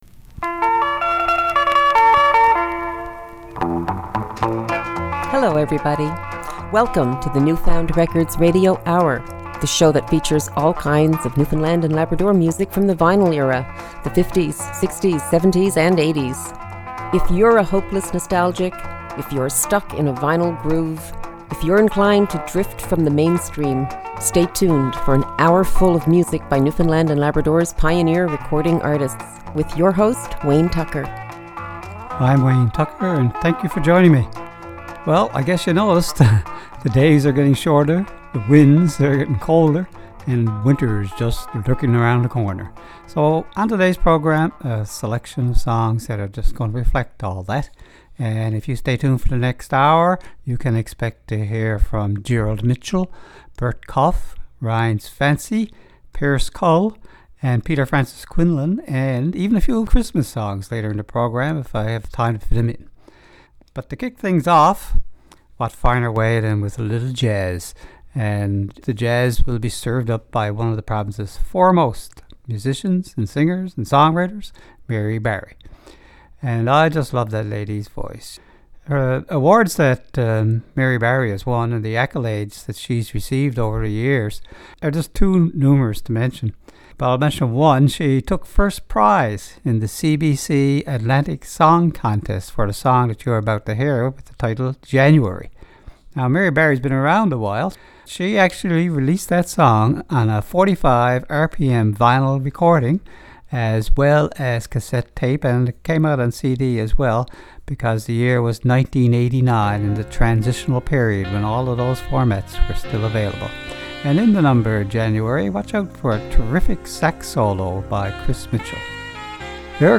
Vinyl records by Newfoundland & Labrador's pioneer recording artists.
Recorded at CHMR studios, MUN, St. John's, NL.